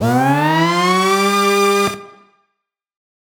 Index of /musicradar/future-rave-samples/Siren-Horn Type Hits/Ramp Up
FR_SirHornB[up]-A.wav